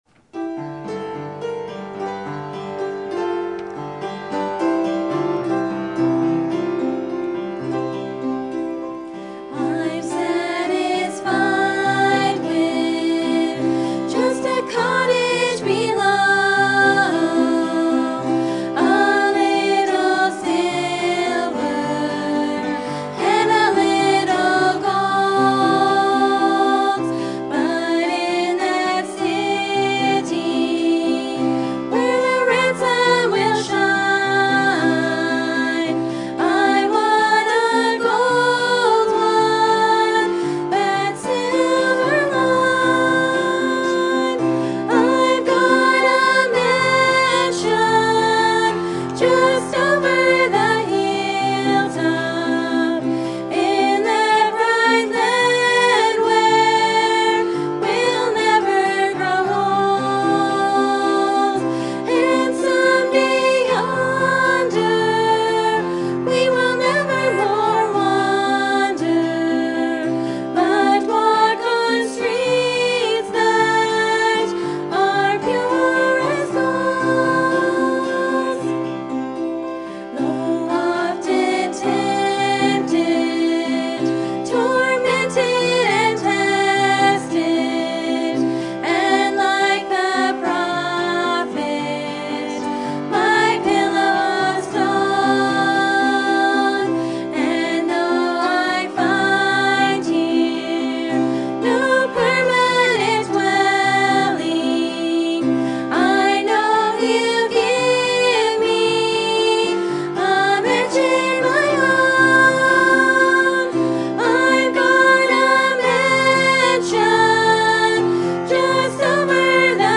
Sermon Topic: Life of David Sermon Type: Series Sermon Audio: Sermon download: Download (27.01 MB) Sermon Tags: 2 Samuel Life of David Deliverer Praise